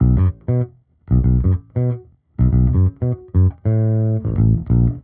Index of /musicradar/dusty-funk-samples/Bass/95bpm
DF_JaBass_95-B.wav